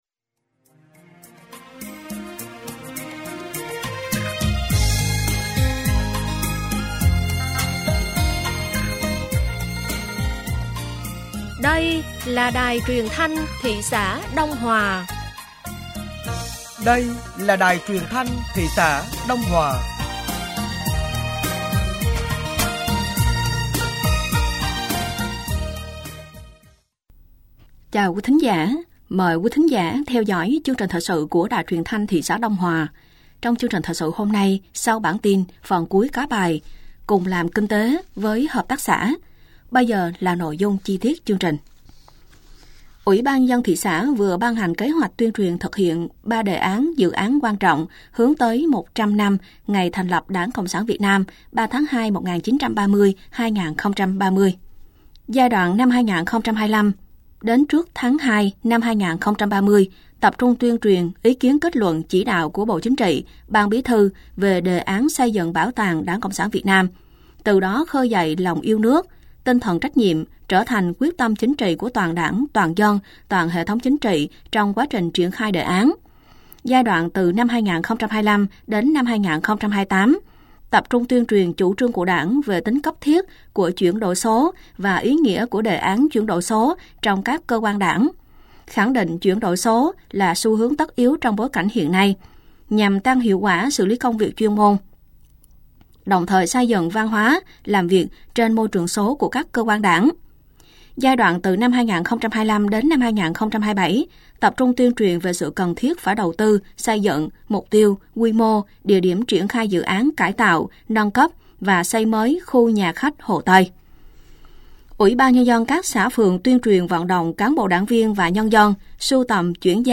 Thời sự tối ngày 30 và sáng ngày 31 tháng 3 năm 2025